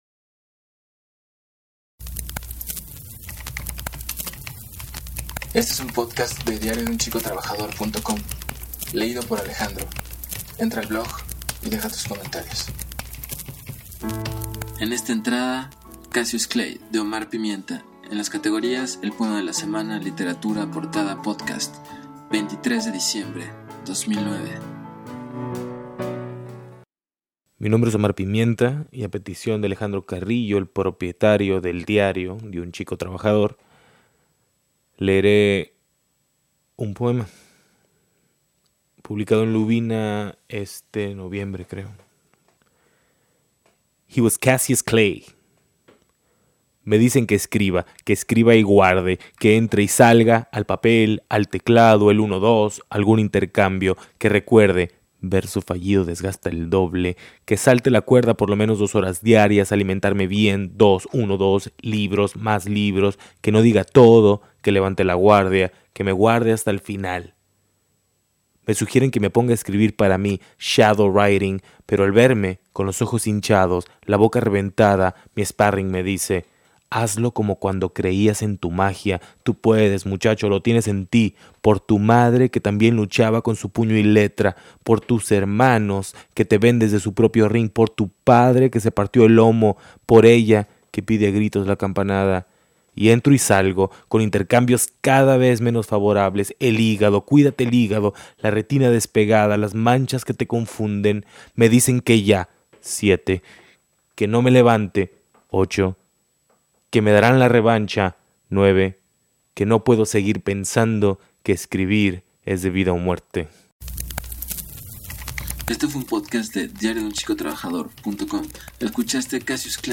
El poema ideal para inaguarar esta sección y, de la mejor manera, ya que podrán oir el poema en la voz del autor.